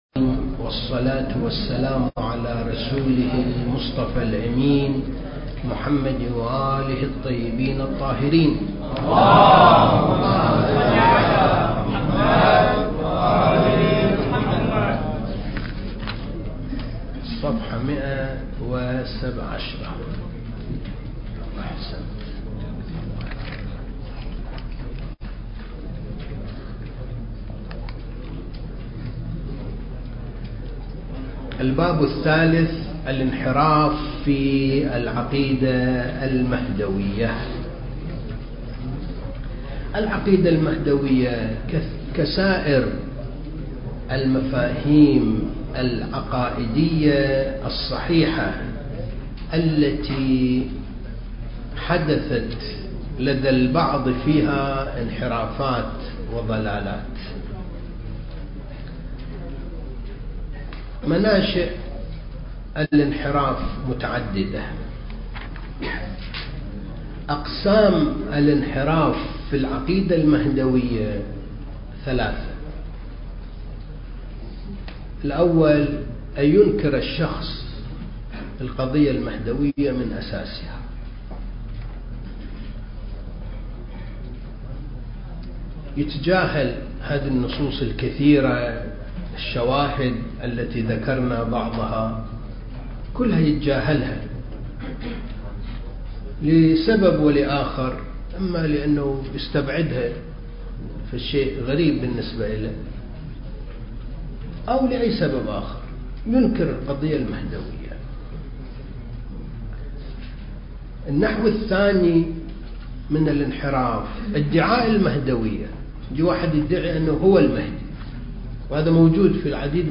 دورة الثقافة المهدوية (6) المكان: معهد وارث الأنبياء (عليهم السلام) لإعداد المبلغين العتبة الحسينية المقدسة